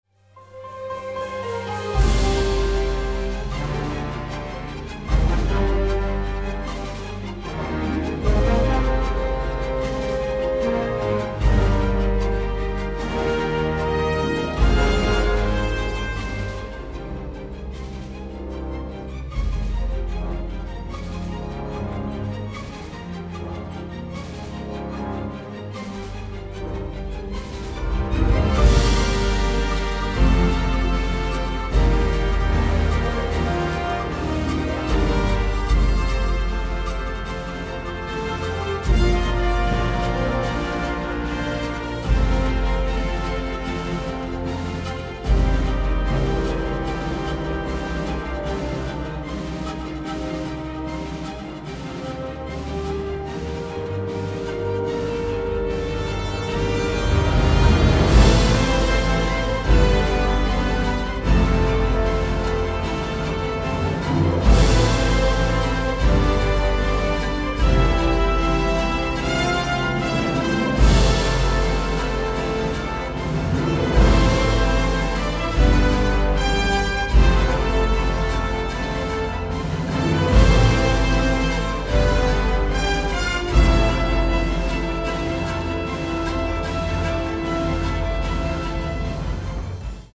propulsive orchestral score
Teeming with smoldering drama and explosive action